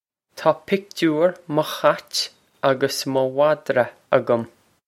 Pronunciation for how to say
Taw pick-toor muh khwitch og-us muh vod-ra ag-um.
This is an approximate phonetic pronunciation of the phrase.